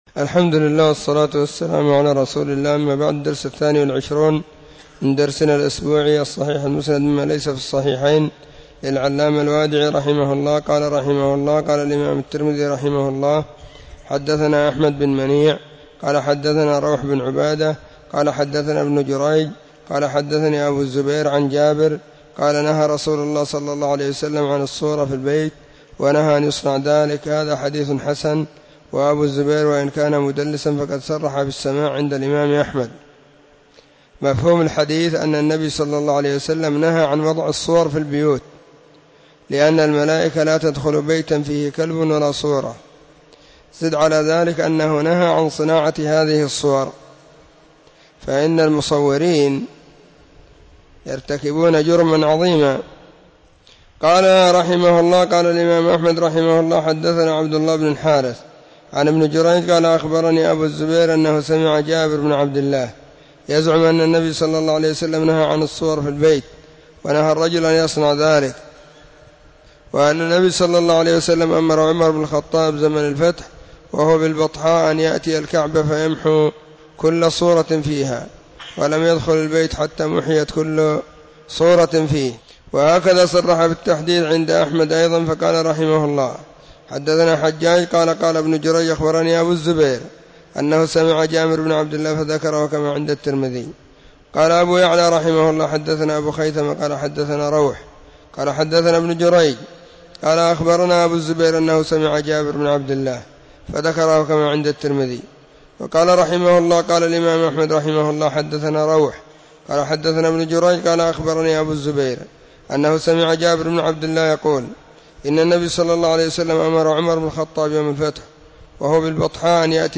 📖 الصحيح المسند مما ليس في الصحيحين , الدرس: 22
خميس -} 📢مسجد الصحابة – بالغيضة – المهرة، اليمن حرسها الله.